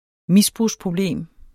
Udtale [ ˈmisbʁus- ]